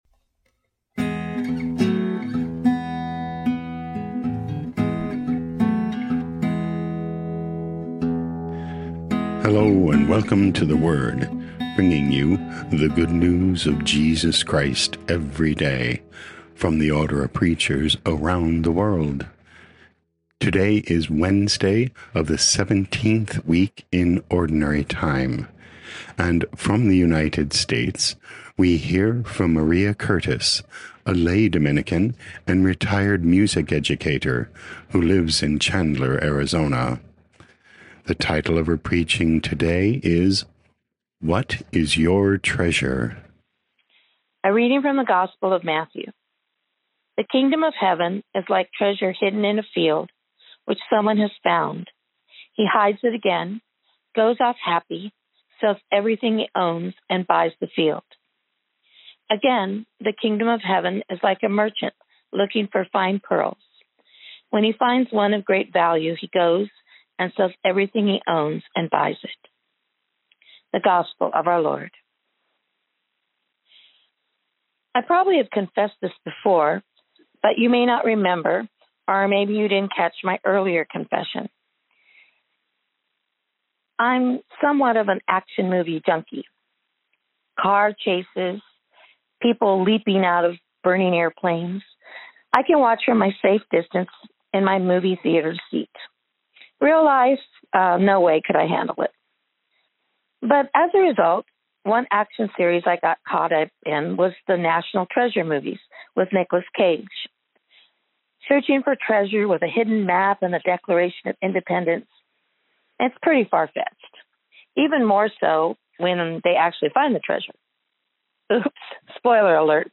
theWord – daily homilies from the Order of Preachers